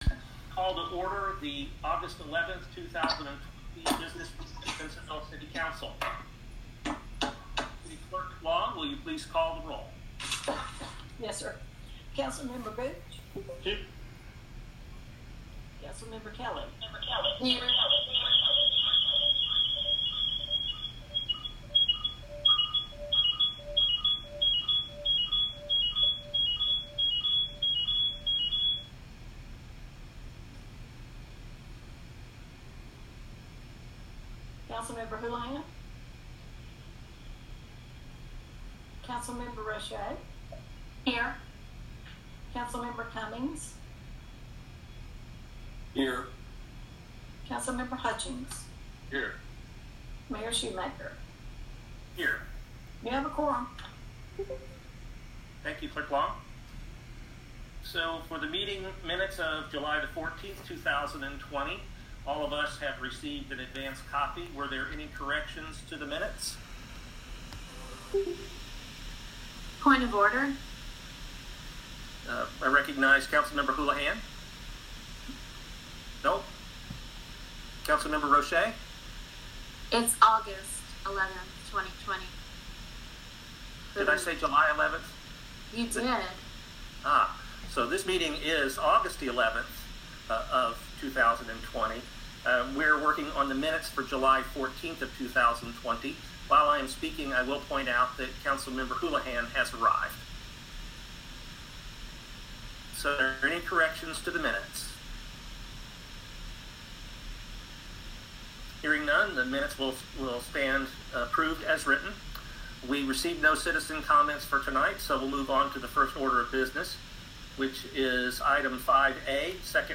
City Council Business Meeting
Simpsonville City Council will hold a regularly scheduled business meeting on Aug. 11, 2020 at 6:30 p.m. via an online meeting service that will be live streamed via the City of Simpsonville YouTube Channel.